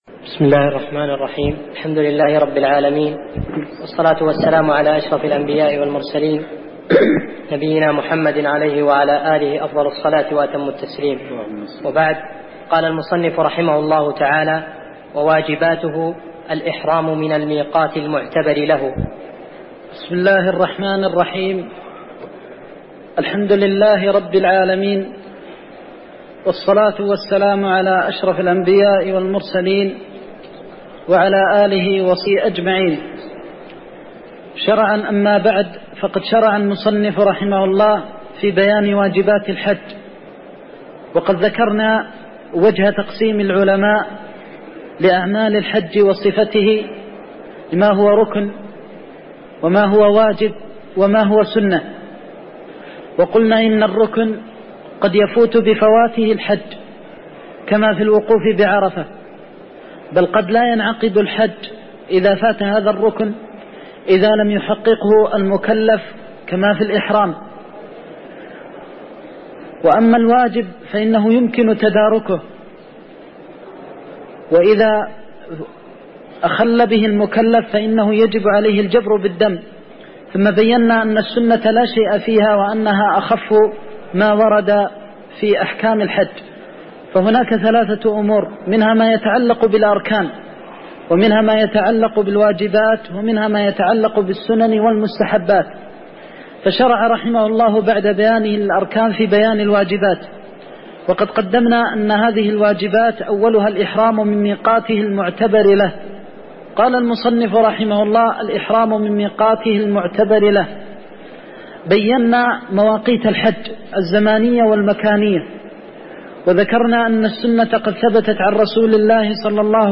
تاريخ النشر ١٢ ذو القعدة ١٤١٧ هـ المكان: المسجد النبوي الشيخ